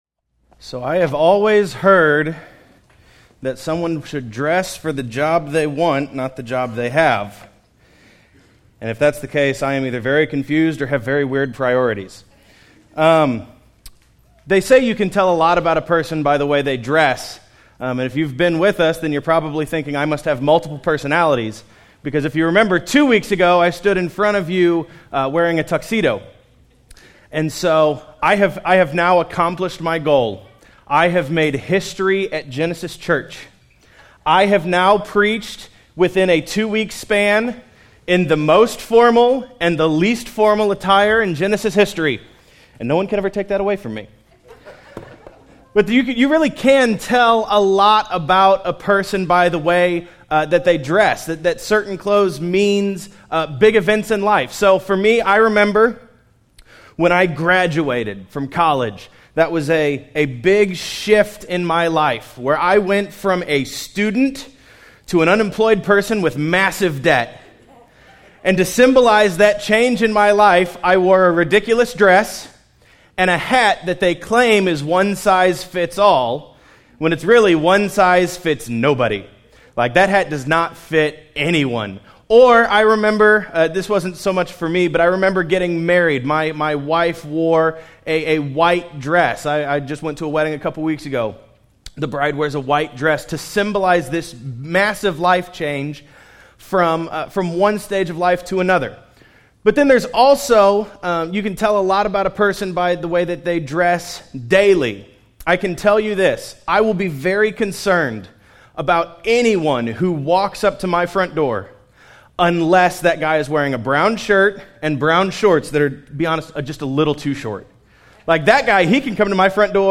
This week, we are discussing how that effects our daily lives. If Christ has raised us from the dead, then we can never be the same person again. In the last sermon in this series from Colossians, Paul is going to tell us how we should live now that we are made alive in Christ and how to